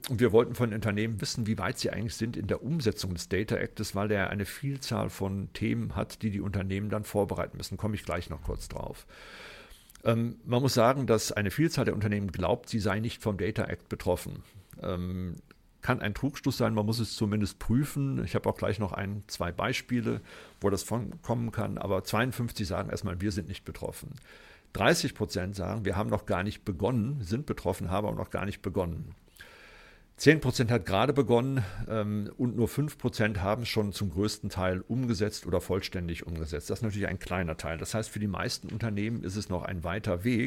Mitschnitte der Pressekonferenz
bitkom-pressekonferenz-data-economy-umsetzungsstand-data-act.mp3